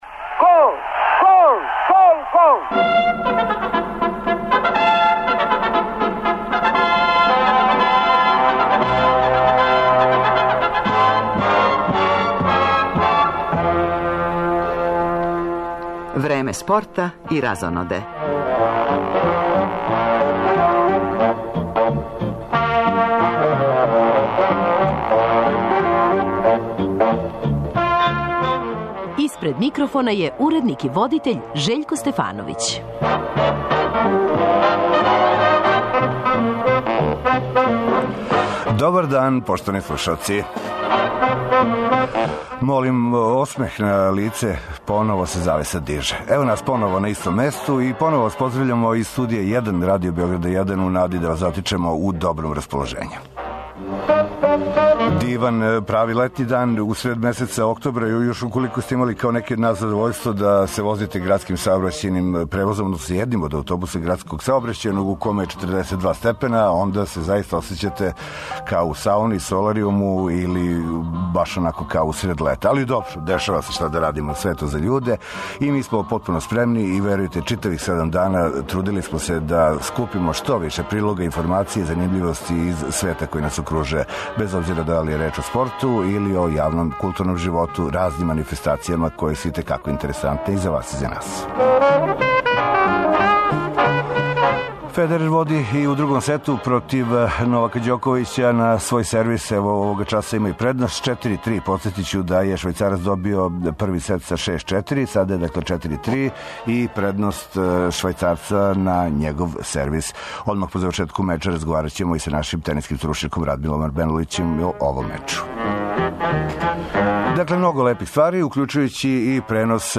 Пренос утакмице предвиђен је за 18 часова.